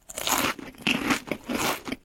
eatfood.ogg